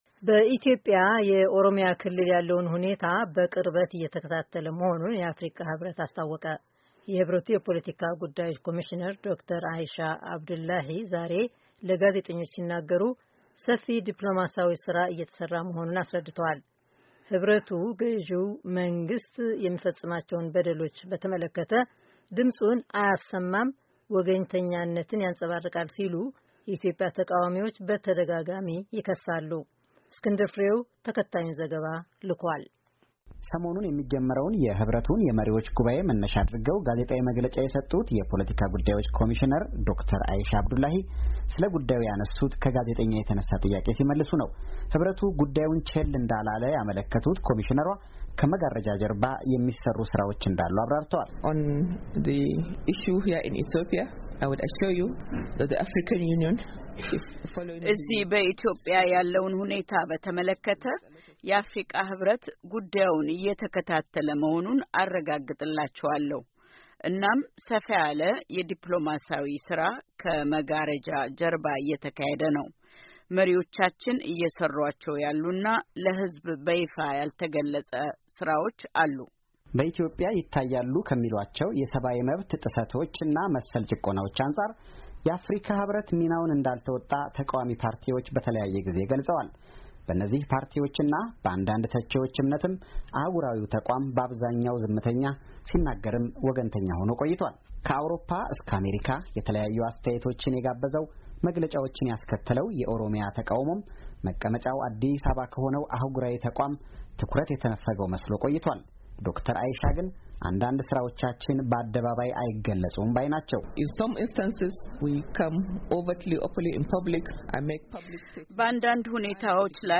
ዋሽንግተን ዲሲ —